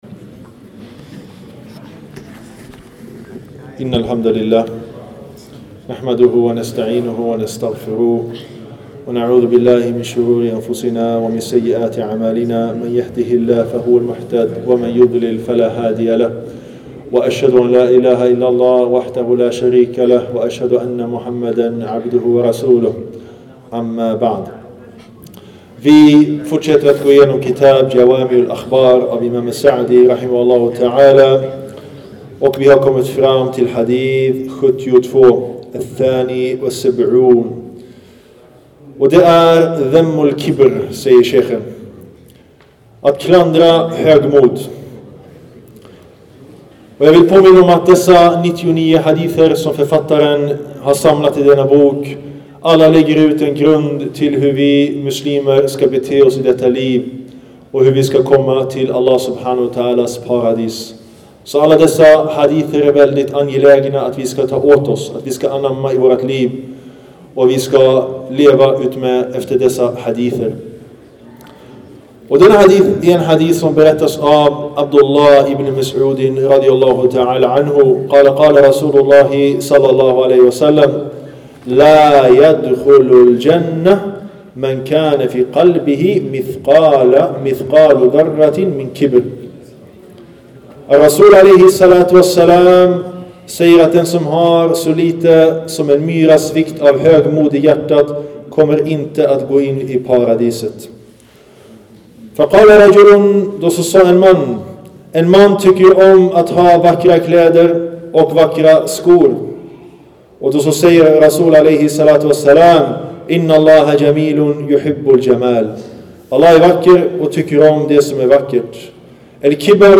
En föreläsning